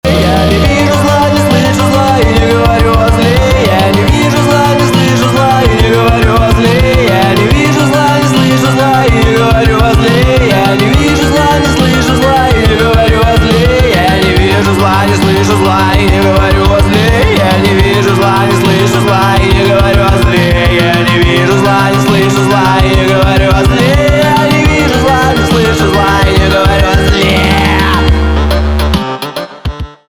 инди